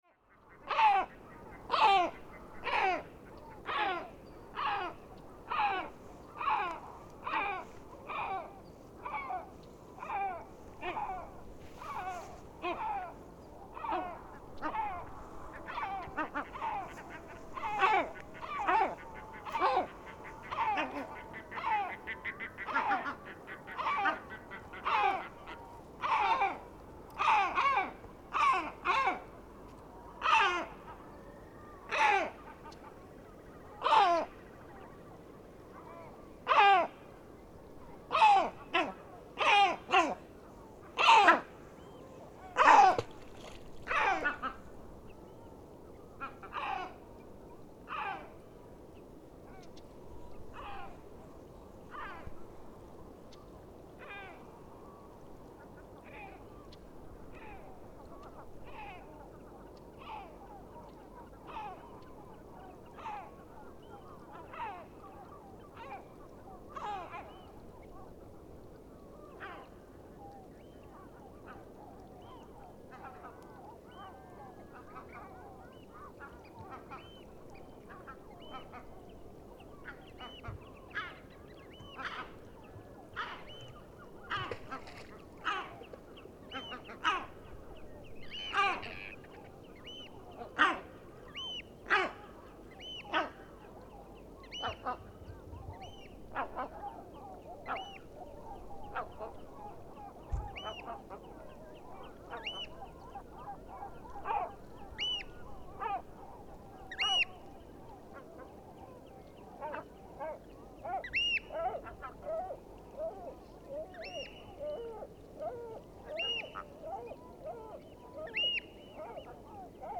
This recording was made on July 11, when Summer nights are bright. As often happened this summer, the birdlife was extremely silent and distant.
I placed the microphones in shelter under the shoreline and pointed them out to sea. The tide was in the middle and the wind was out to sea so the waves were calm.
At the end, most likely a raven sits right next to the microphone, probably hoping to have found food, but flies away when it turns out that the microphones did not resemble as two dead fury animals. The recording was done with 48dB gain in the audio processing, it was increased by 23dB up to -5dB.